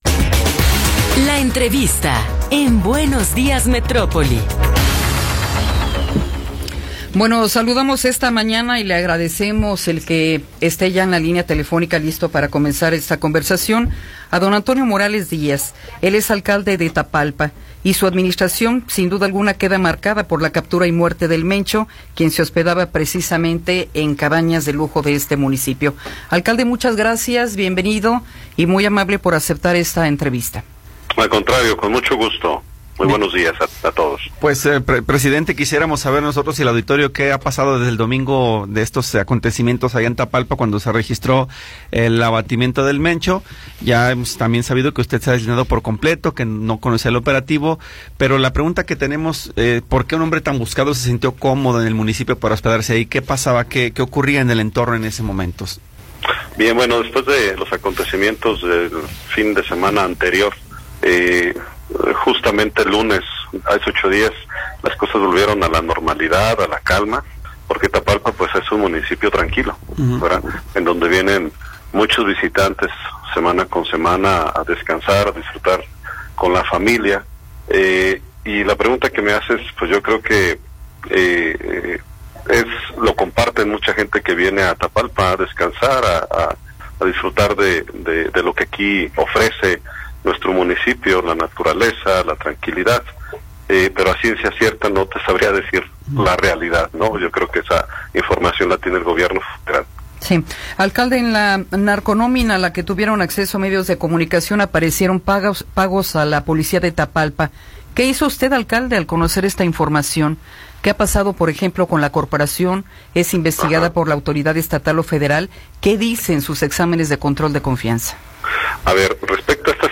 Entrevista con Antonio Morales Díaz
Antonio Morales Díaz, alcalde de Tapalpa, nos habla sobre el operativo en el cual fue abatido “El Mencho” y la situación actual en el municipio.